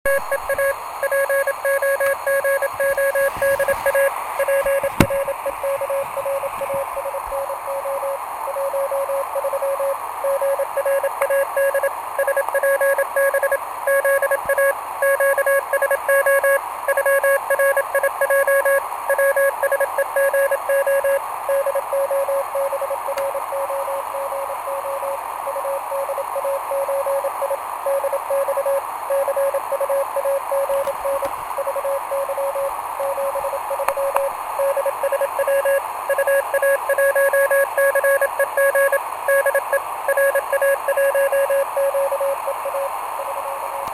Шикарно человек работает CW!